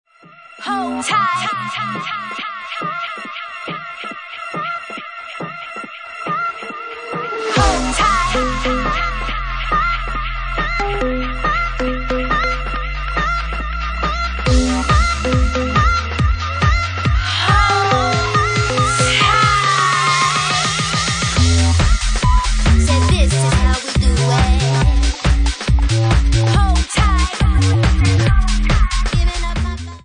Genre:Bassline House
Bassline House at 139 bpm